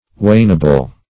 Search Result for " wainable" : The Collaborative International Dictionary of English v.0.48: Wainable \Wain"a*ble\, a. Capable of being plowed or cultivated; arable; tillable.